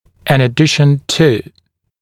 [ɪn ə’dɪʃn tuː][ин э’дишн ту:]в дополнение к, вдобавок к